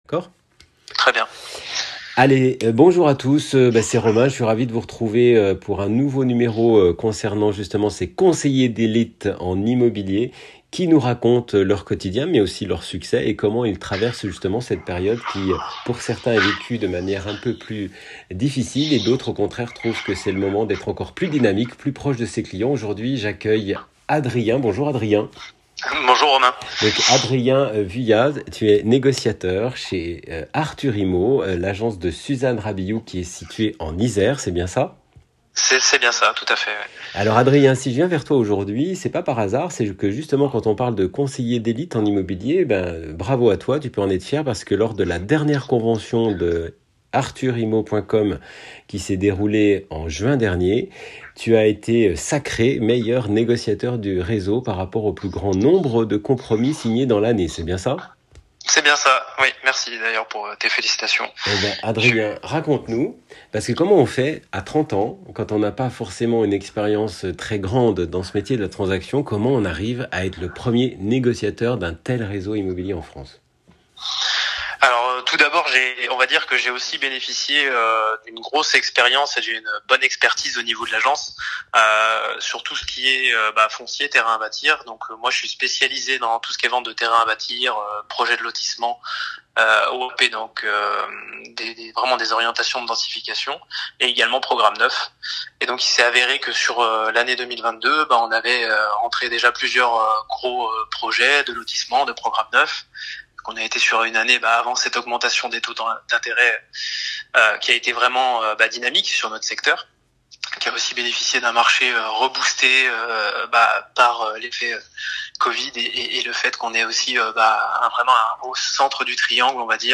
Avec un ton sincère et un discours très franc, voici un dirigeant qui n’hésite pas à dire les choses.